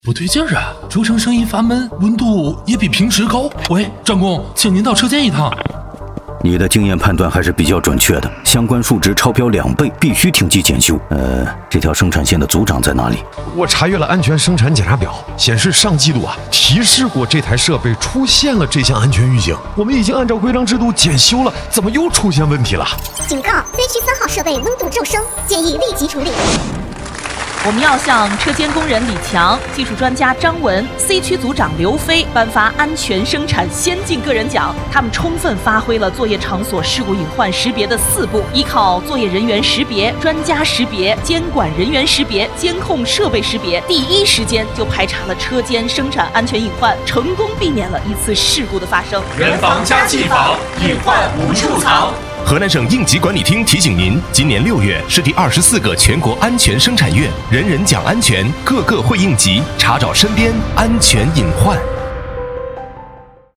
政务广告-应急管理厅安全生产月03-工厂篇